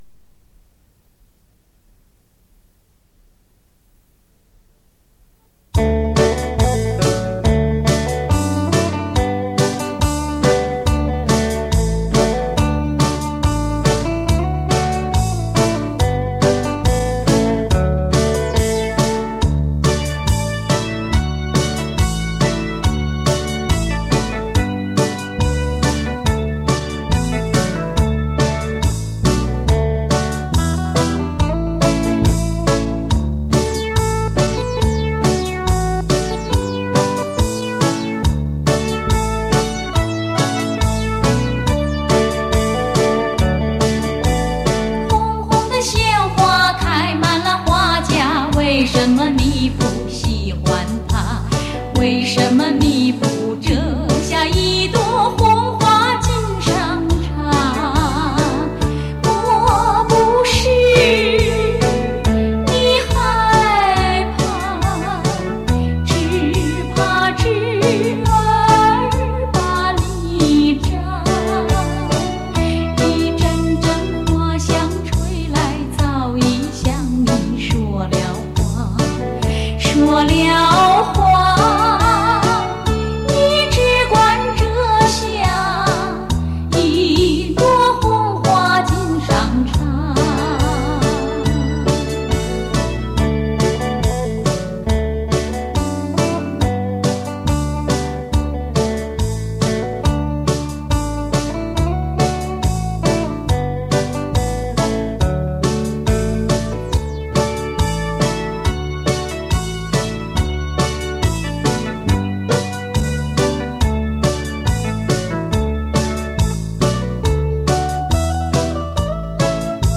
镭射数码录音